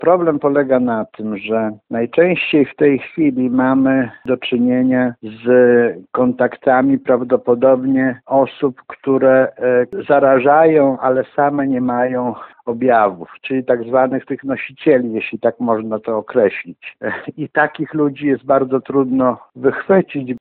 Jak dodaje Nikliński, trudno jest wskazać konkretne ognisko zakażeń na terenie powiatu.